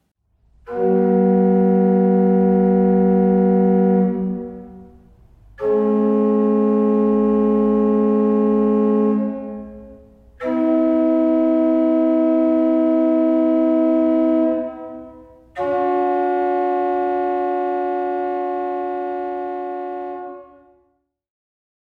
Bonustrack: Hörbeispiel: Vier angespannte Terzen